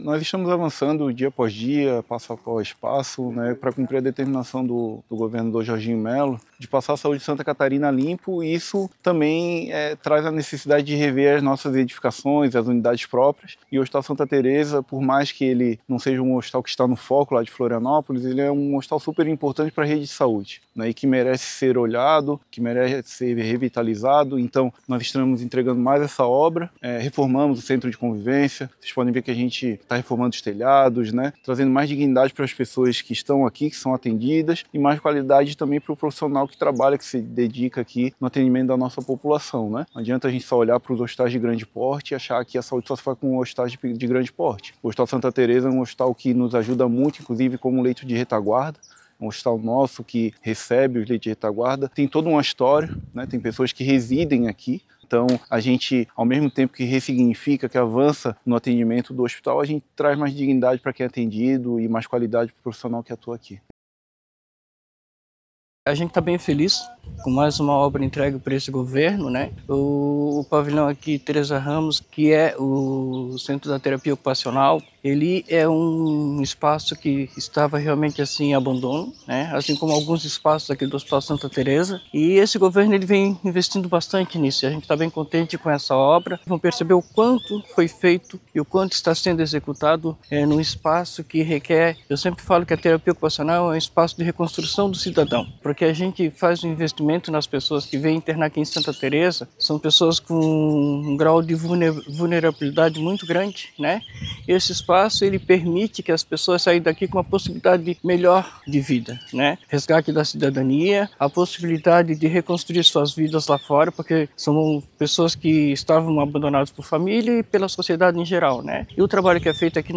Para o secretário de Estado da Educação, Diogo Demarchi, a entrega representa mais um avanço no cuidado com a saúde dos catarinenses: